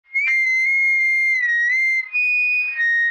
Звуки рупора
Противный скрип в мегафоне с фоновым шумом